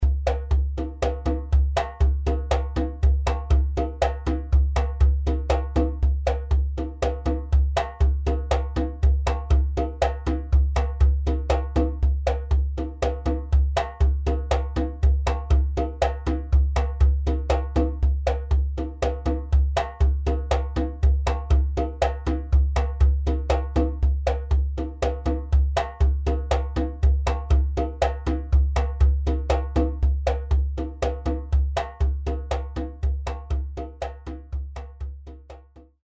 djembé1-01.mp3